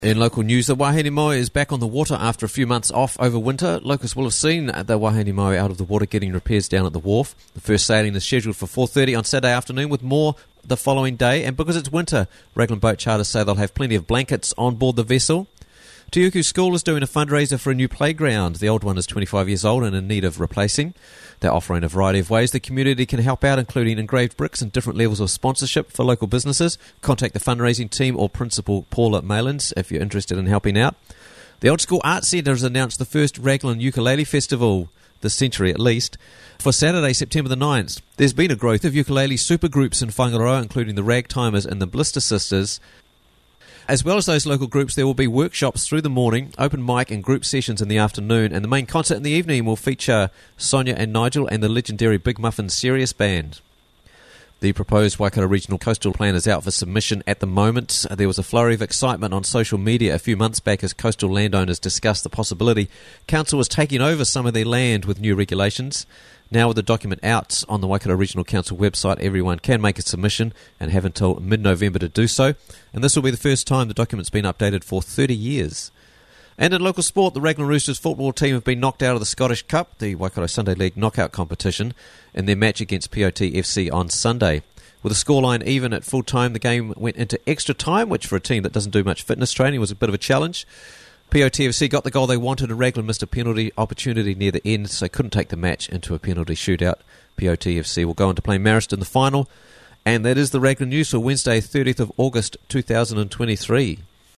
Raglan News Wednesday 30th August 2023 - Raglan News Bulletin